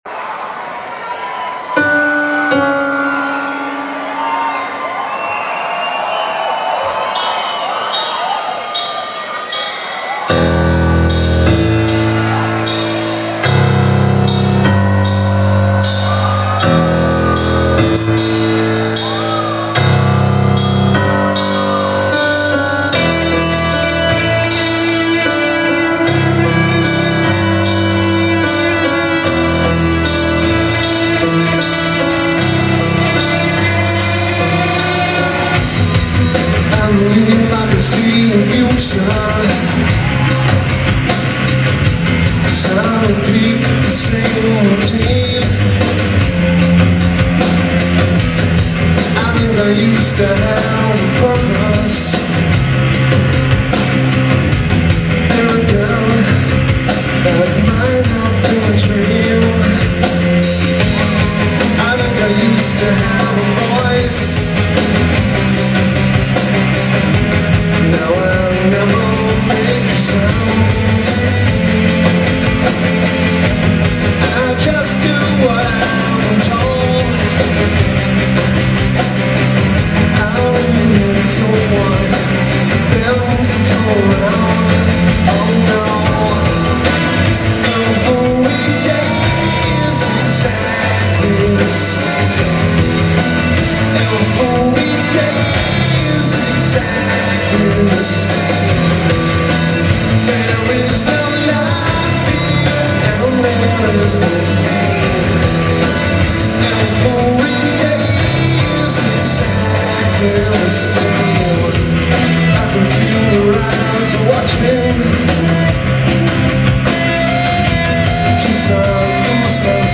Pershing Auditorium